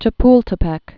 (chə-pltə-pĕk)